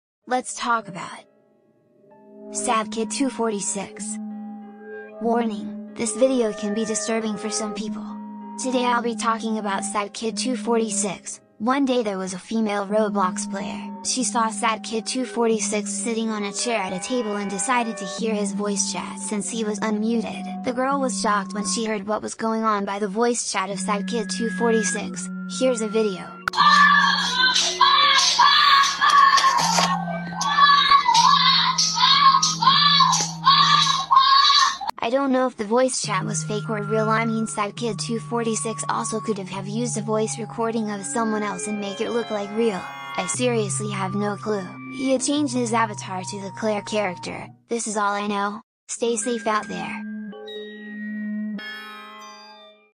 TikTok, it’s an AI Voice sound effects free download